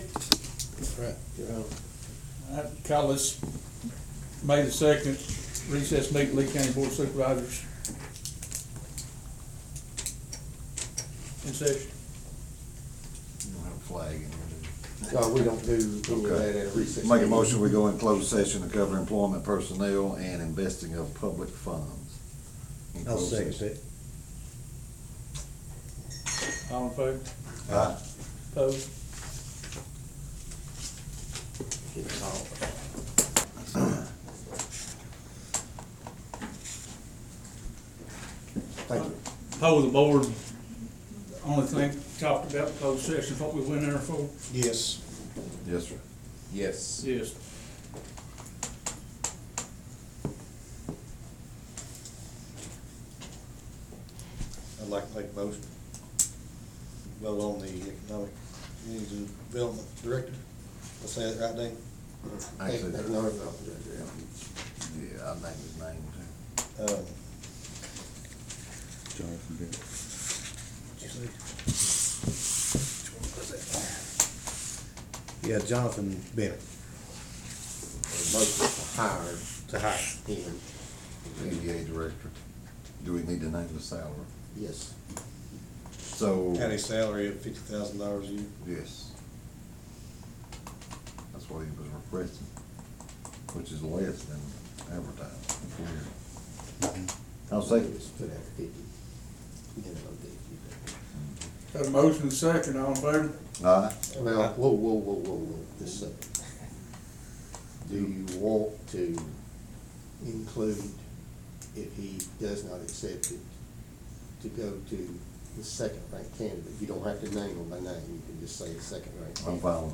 May 2, 2024 Recessed Meeting.mp3